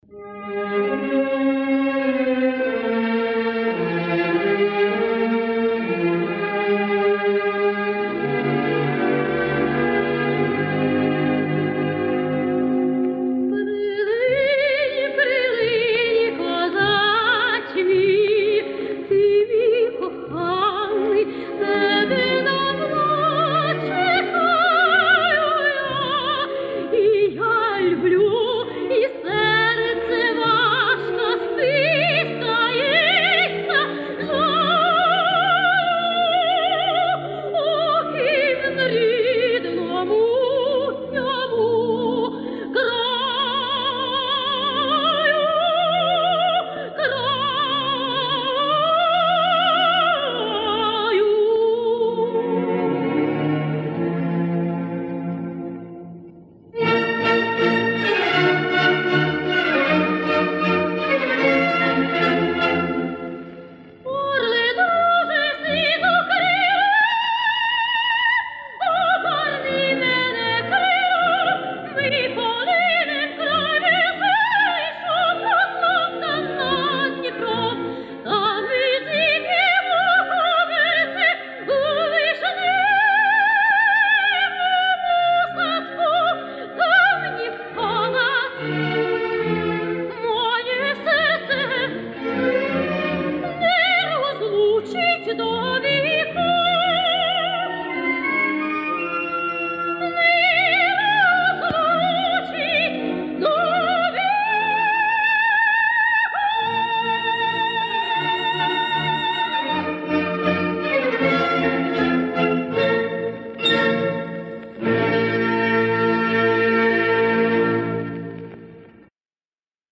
Пісня Наталки